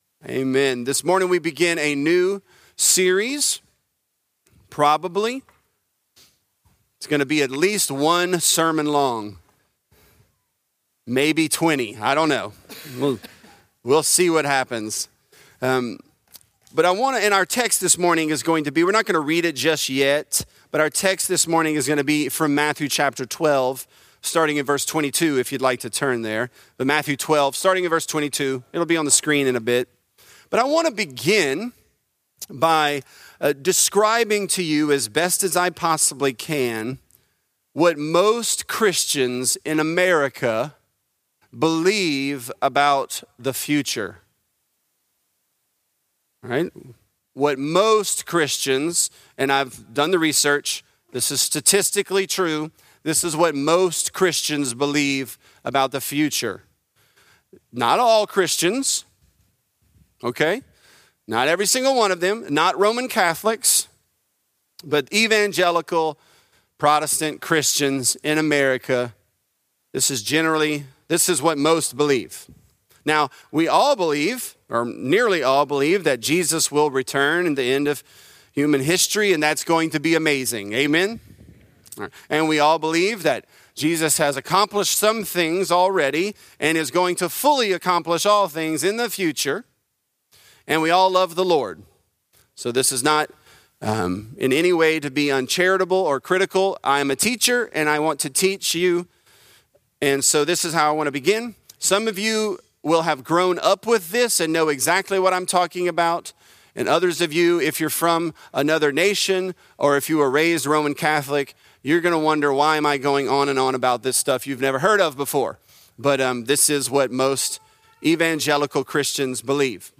The Kingdom Has Come | Lafayette - Sermon (Matthew 12)